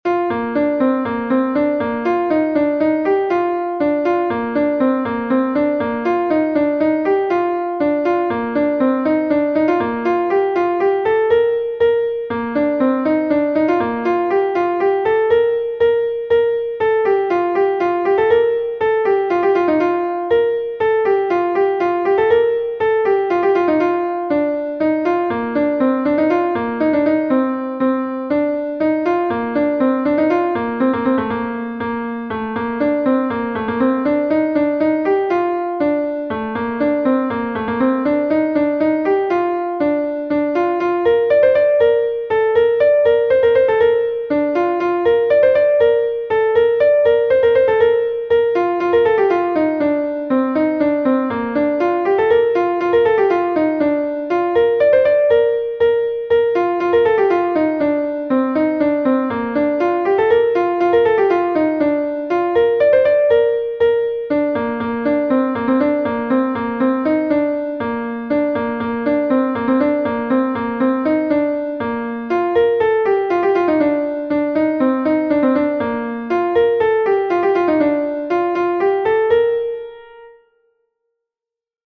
Gavotenn Sant-Tudal II est un Gavotte de Bretagne